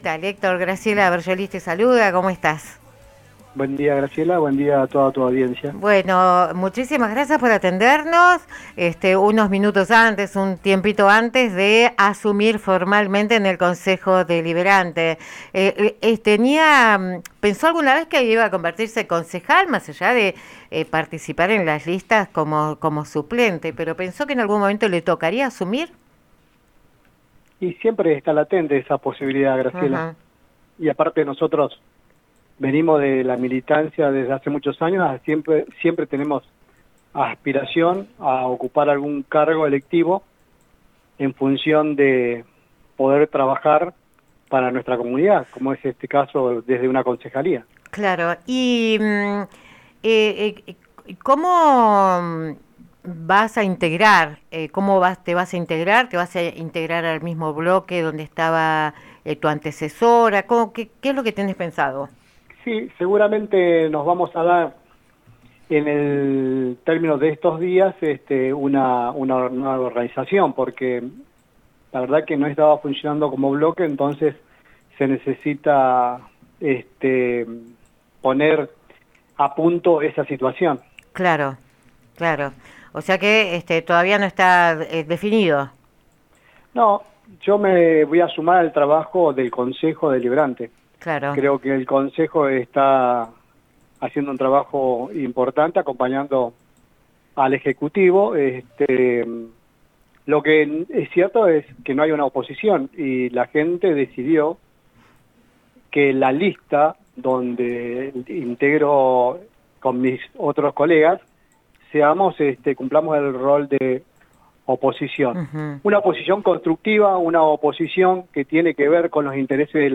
Entrevista a Lorena Matzen, legisladora UCR. 03 de marzo 2026